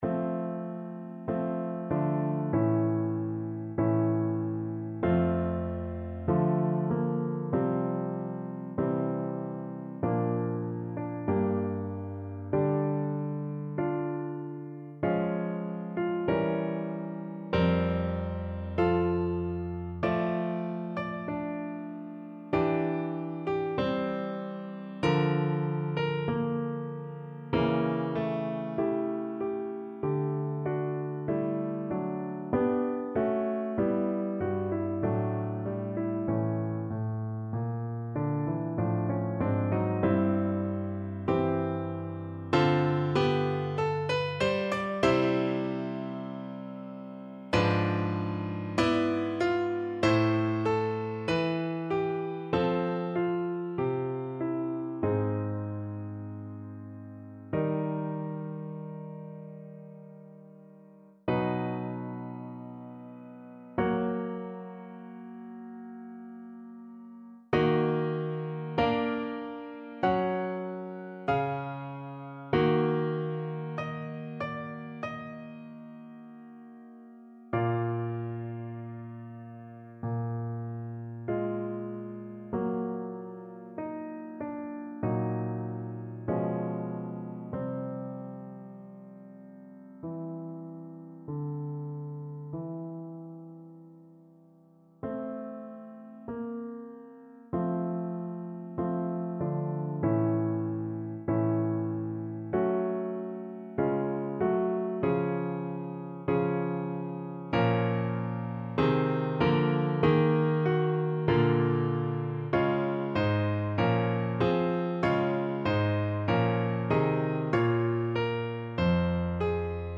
Classical Bruckner, Anton Ave Maria, WAB 7 Piano version
No parts available for this pieces as it is for solo piano.
F major (Sounding Pitch) (View more F major Music for Piano )
2/2 (View more 2/2 Music)
~ = 96 Alla breve. Weihevoll.
Piano  (View more Intermediate Piano Music)
Classical (View more Classical Piano Music)